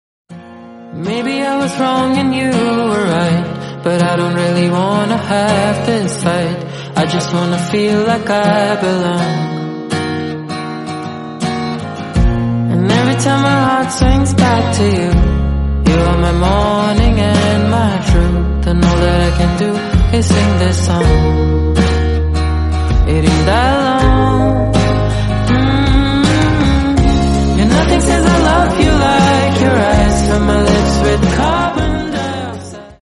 High-quality, catchy tune perfect for your phone.